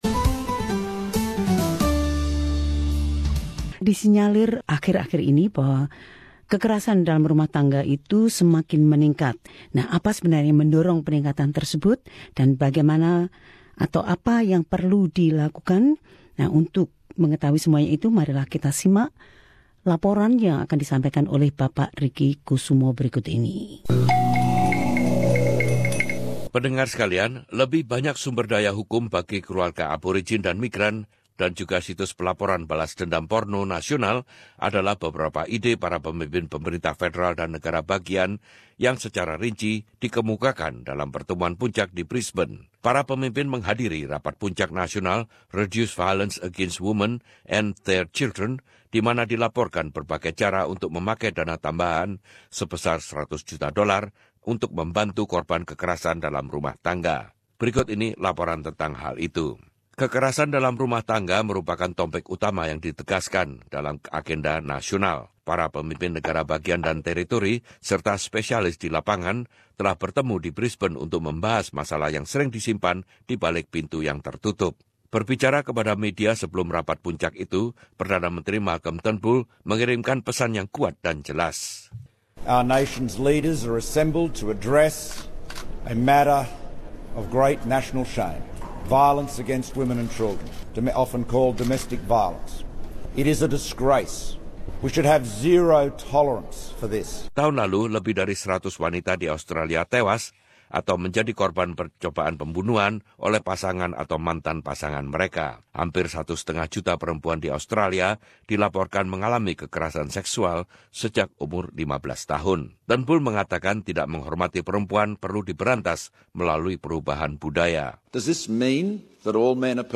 Laporan ini menyimak isu-isu tersebut.